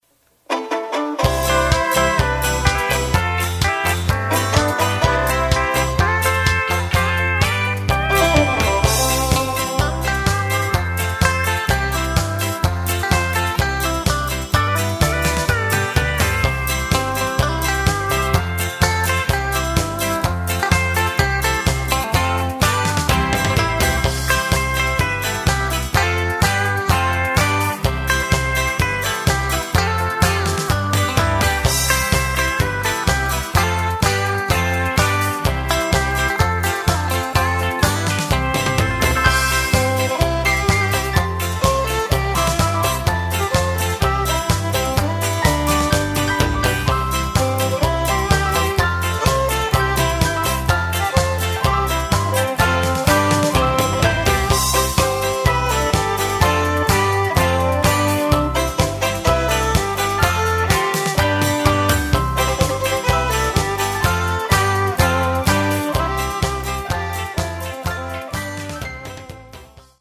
B side has stronger lead